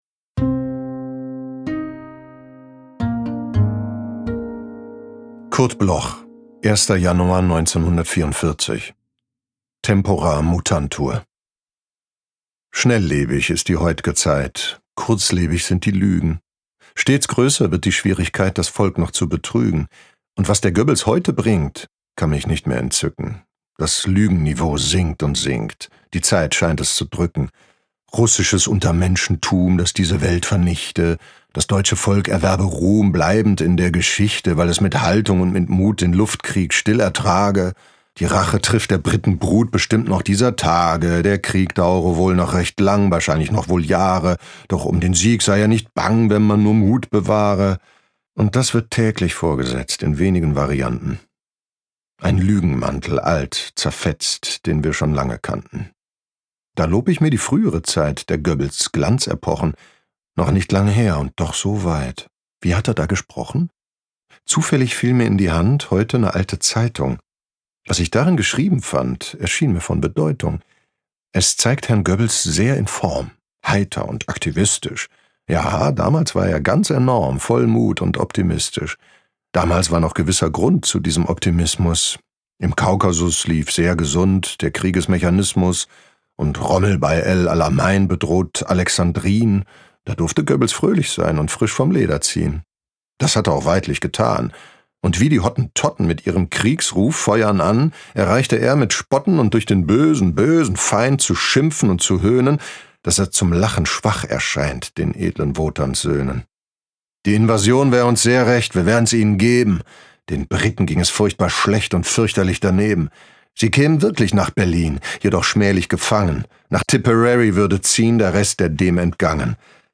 Jörg Hartmann (* 1969) is a German actor, audiobook narrator and screenwriter.
Joerg-Hartmann-Tempora-mutantur-mit-Musik_raw.m4a